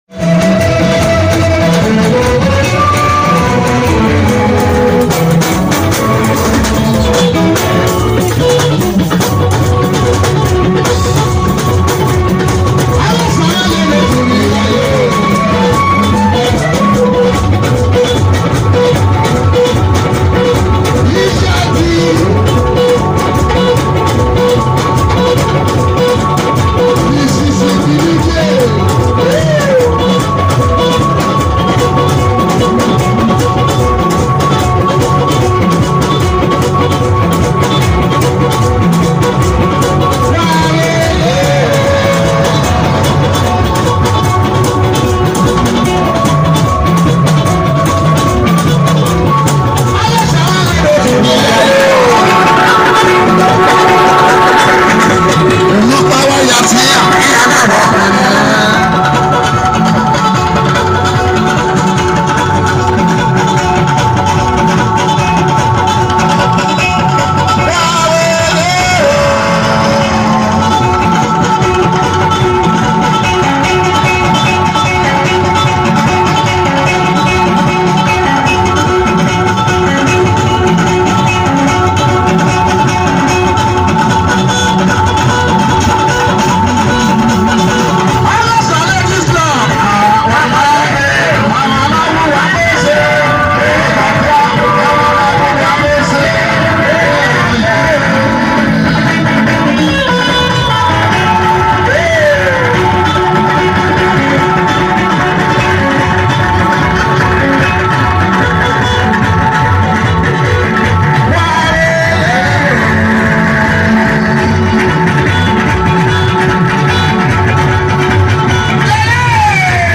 Edo Highlife